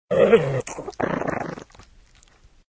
SDogBite.ogg